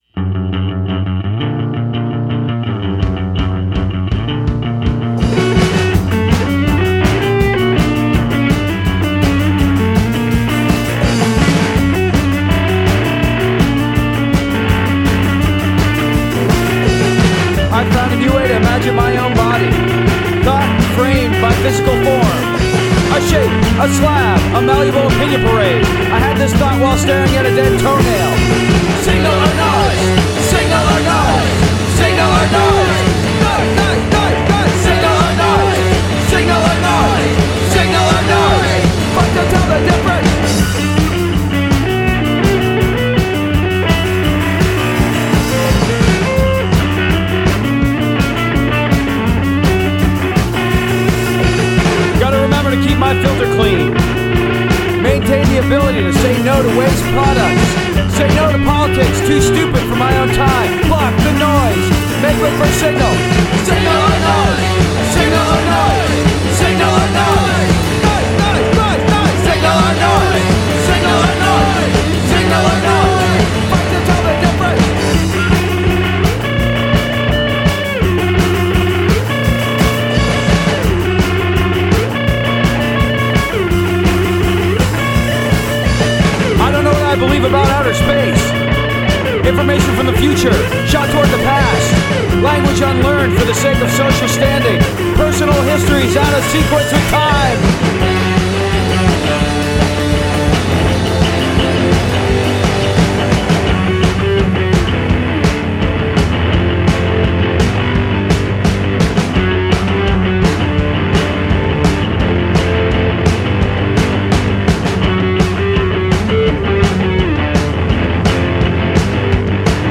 post-punk